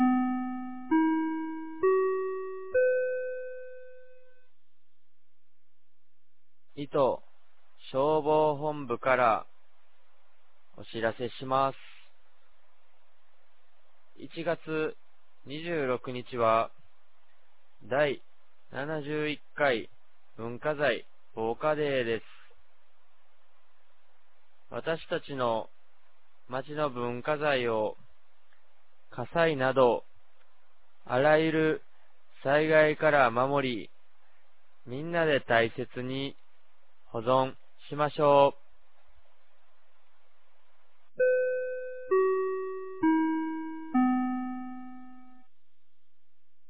2025年01月20日 10時00分に、九度山町より全地区へ放送がありました。